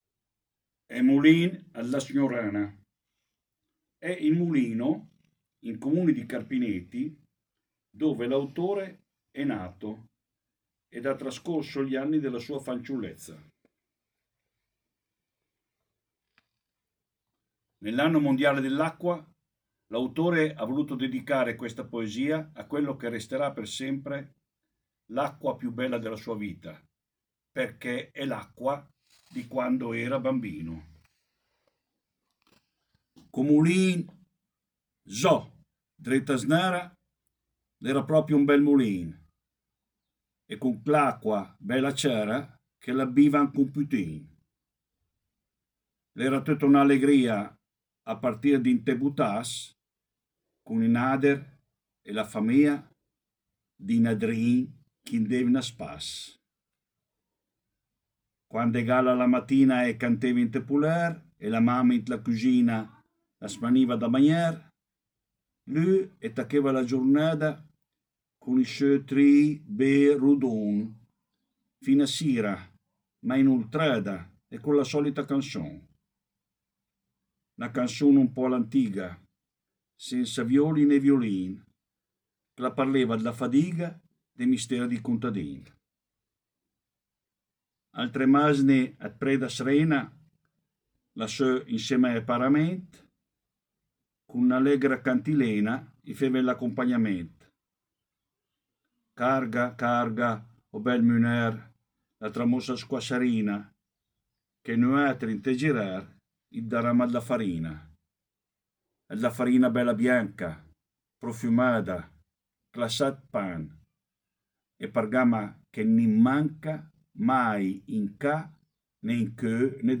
Legge una poesia di Eolo Biagini, dal titolo: